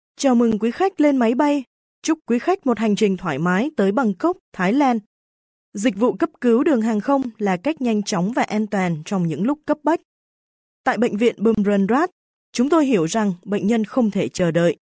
越南语样音试听下载
VN-DV008-female-demo.mp3